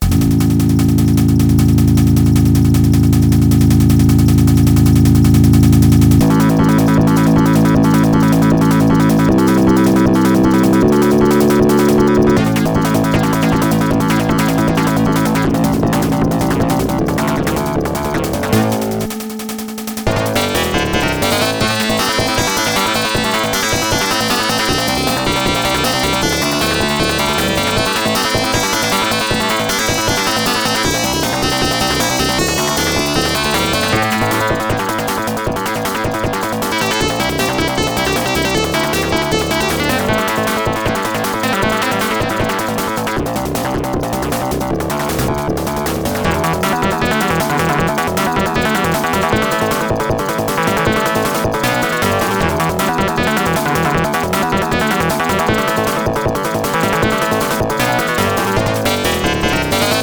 Creative Sound Blaster AWE 64 Value (CT4520) — Unisound